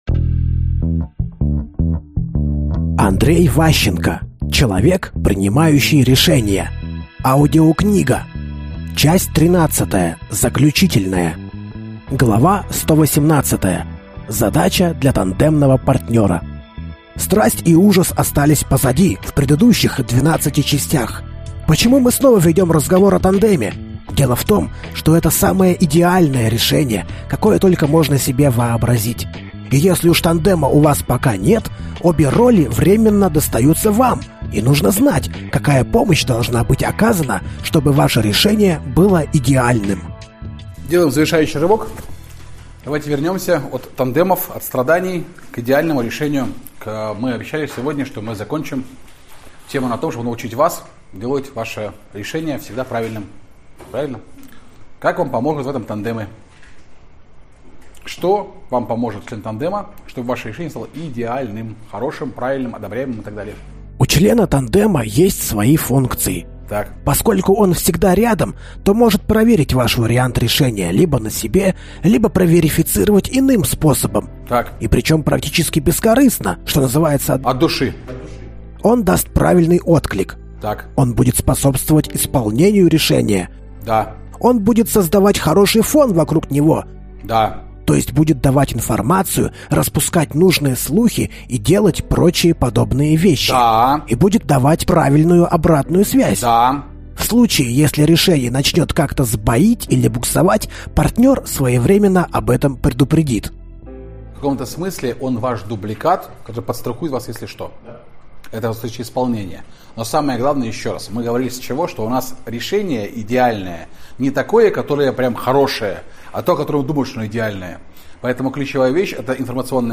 Аудиокнига Человек, принимающий решения. Часть 13 | Библиотека аудиокниг